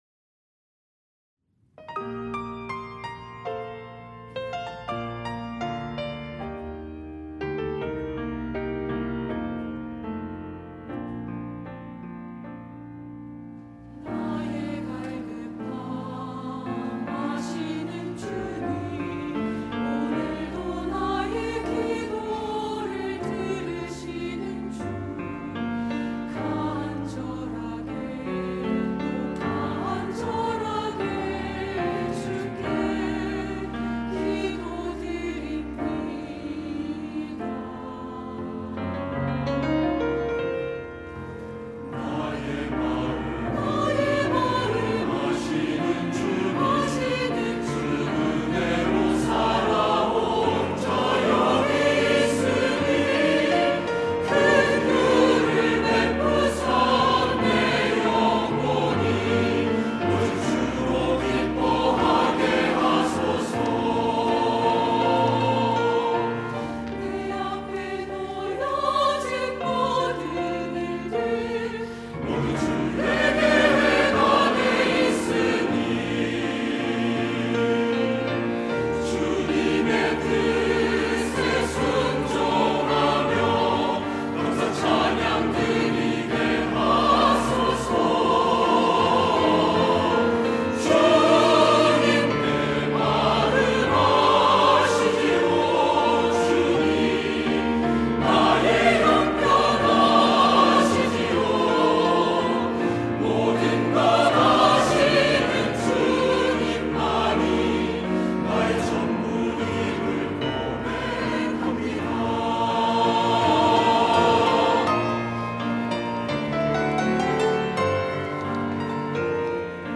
할렐루야(주일2부) - 주님만이 나의 전부입니다
찬양대